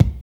X KICK 1.wav